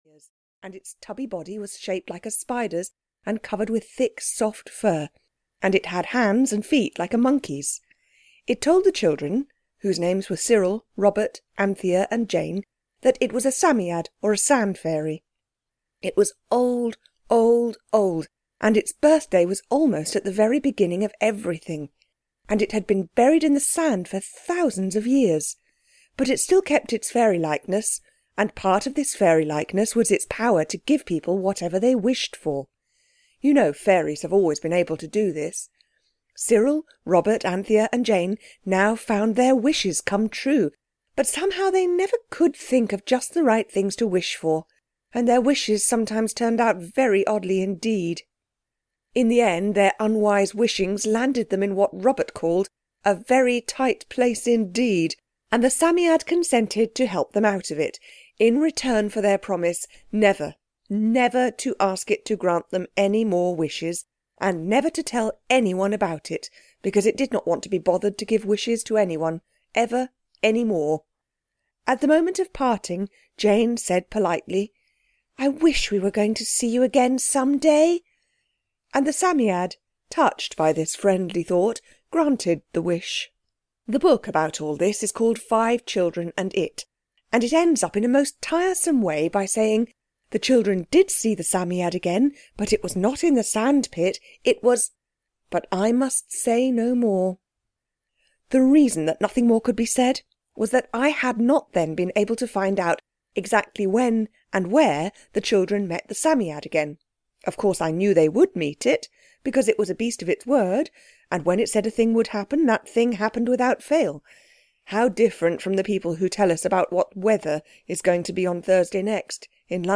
The Story of the Amulet (EN) audiokniha
Ukázka z knihy